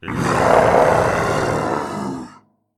hell_dog4.ogg